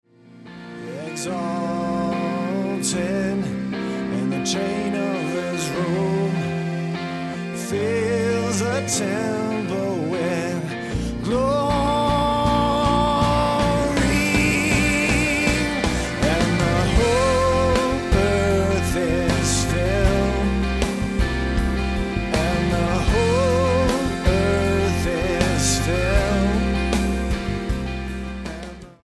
Style: Rock Approach: Praise & Worship